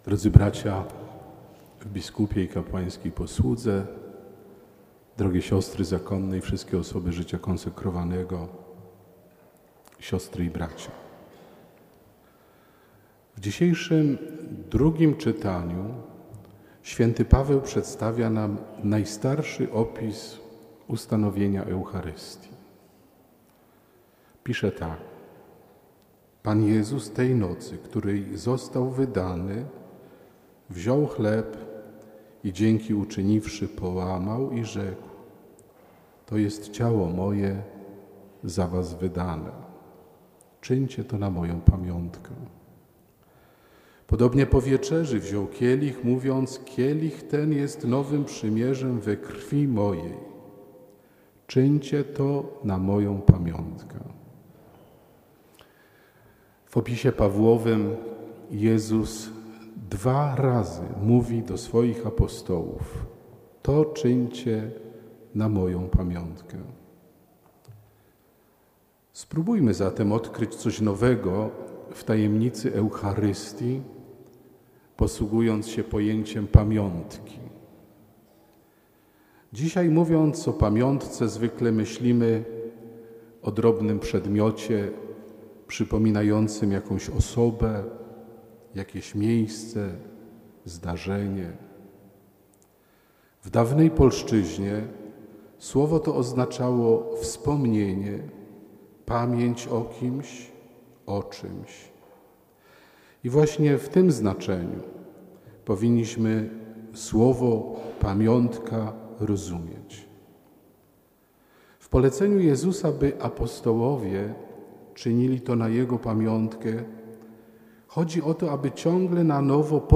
Po Mszy św. w katedrze abp Józef Kupny poprowadził procesję, która zakończyła się w znajdującym się na rynku kościele garnizonowym pw. św. Elżbiety.